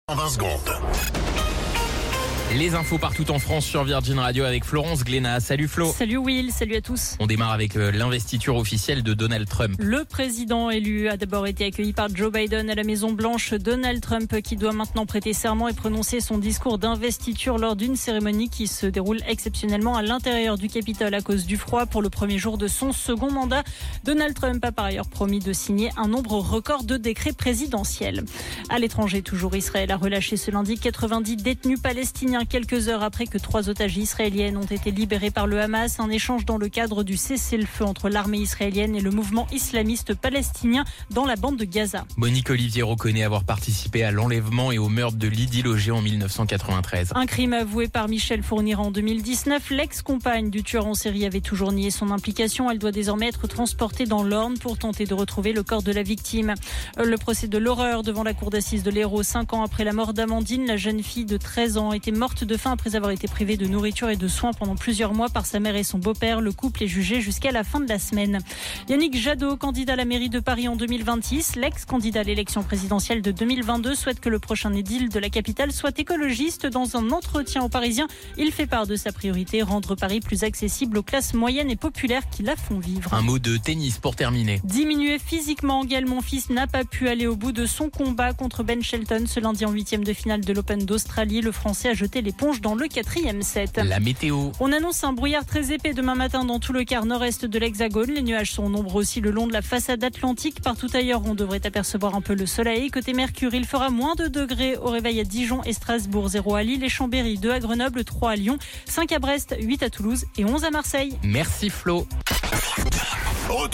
Flash Info National 20 Janvier 2025 Du 20/01/2025 à 17h10 .